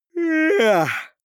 Gemafreie Sounds: Vocals